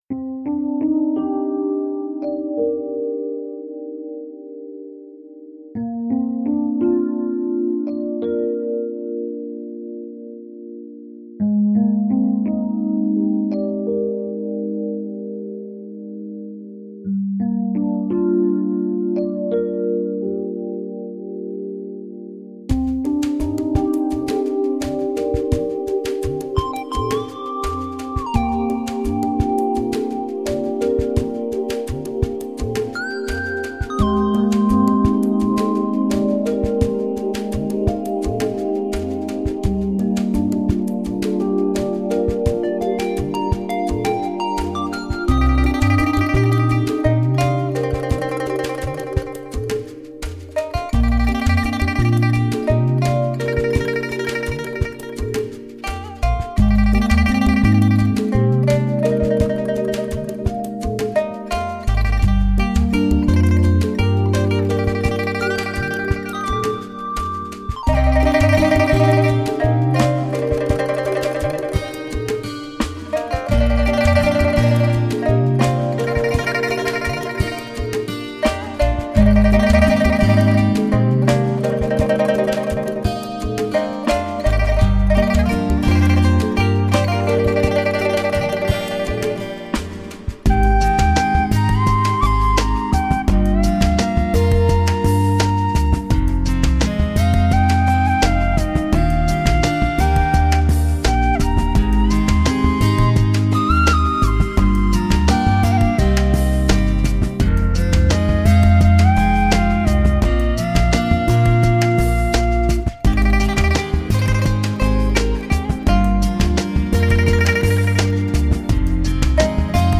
构思精巧 旋律小巧 节奏灵巧 演奏轻巧
唯有水墨画室能与江南丝竹乐相契合的中国艺术